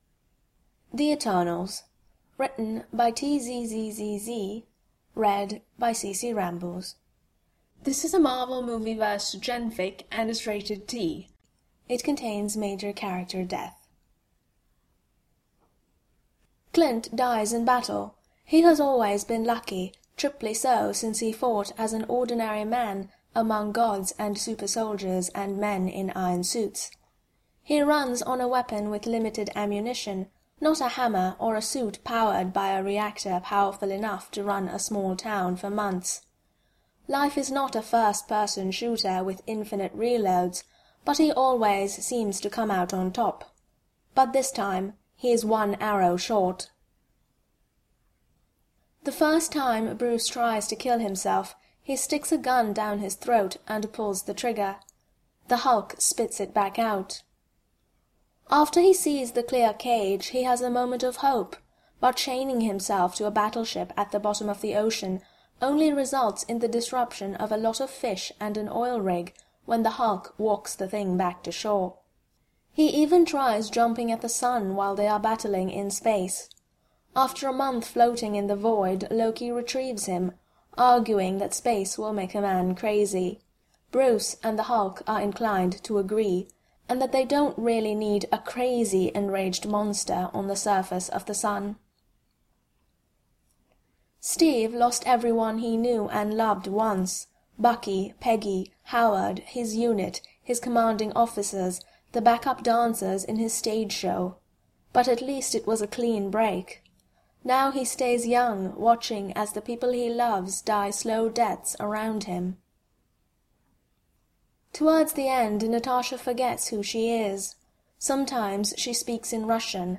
Podfic: The Eternals